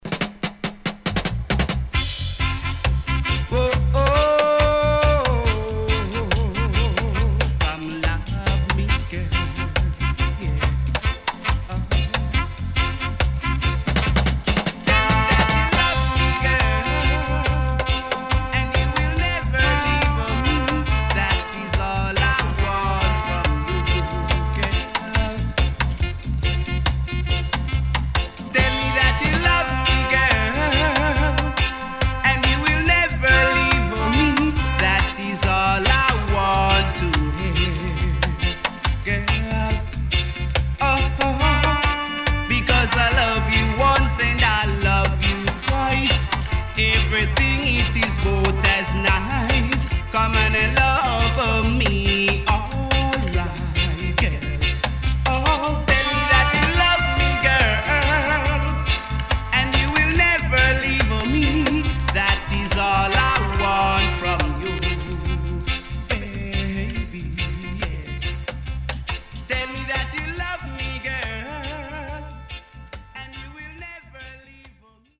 CONDITION SIDE A:VG(OK)〜VG+
SIDE A:所々チリノイズがあり、少しプチパチノイズ入ります。